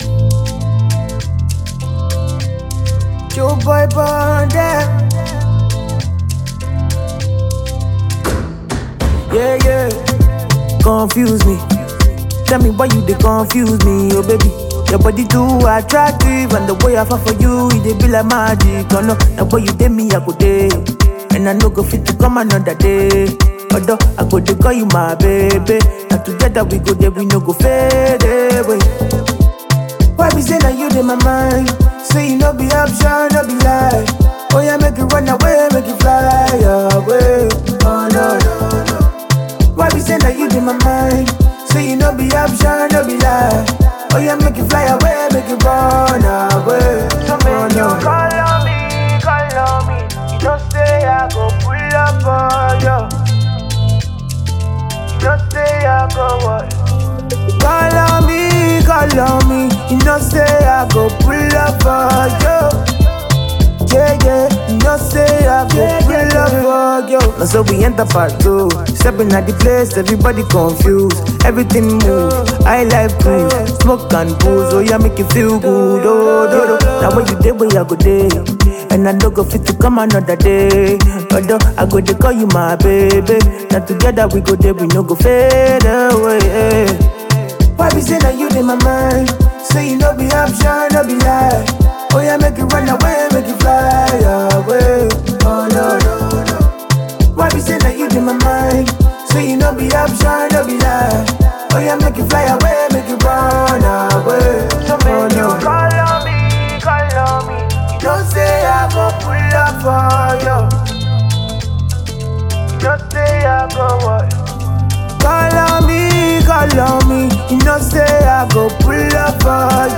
a darker, more urgent edge